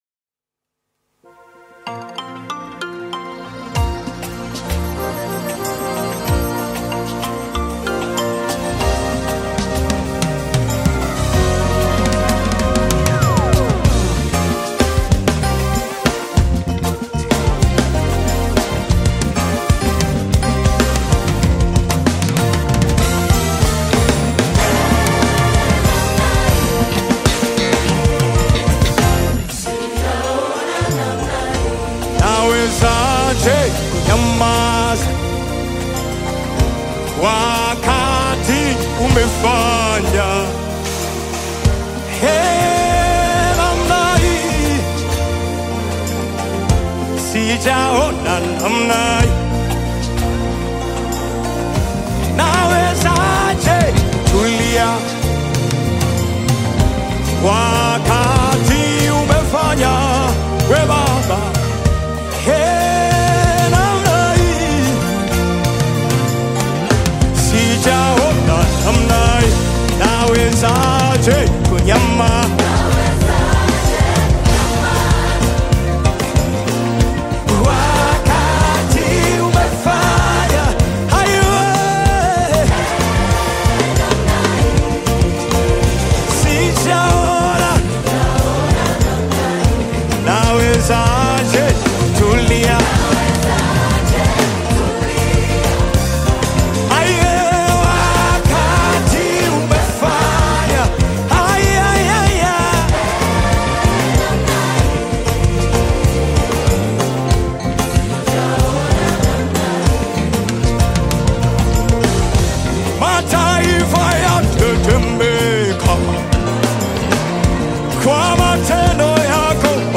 Nyimbo za Dini music
Gospel music track